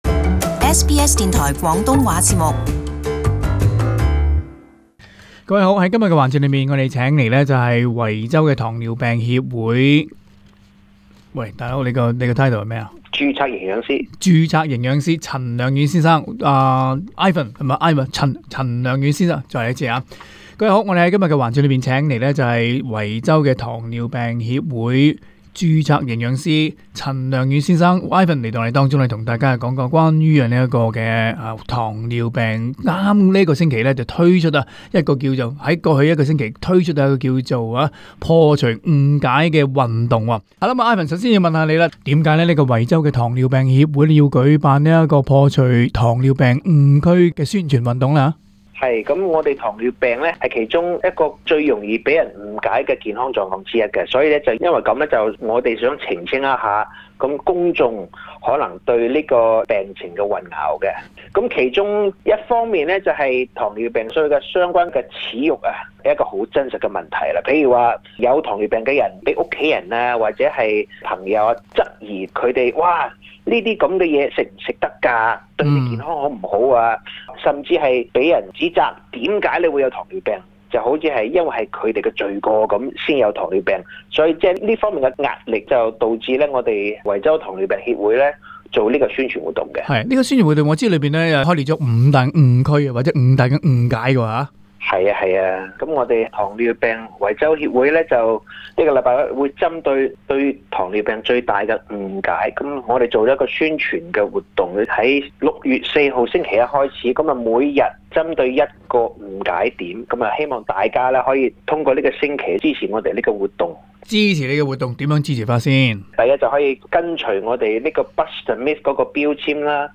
【社團專訪】破除糖尿病誤區運動